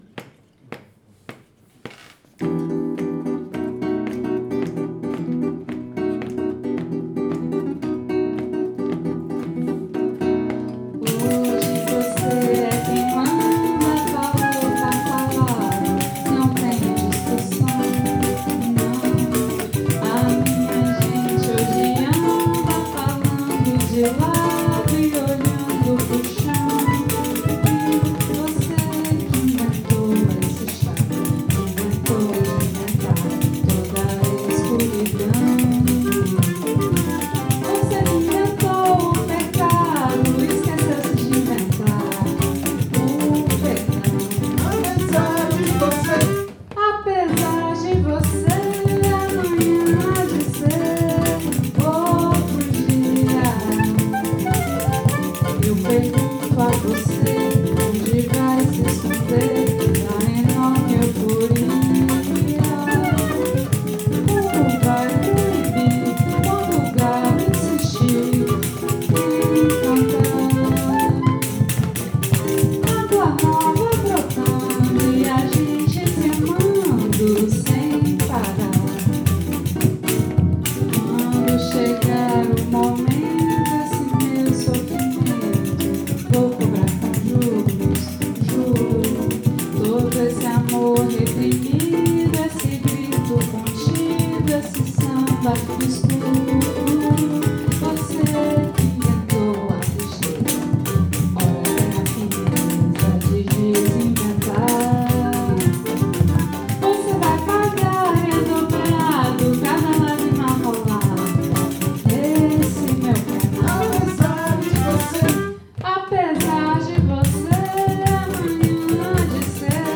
essai sur tempo rapide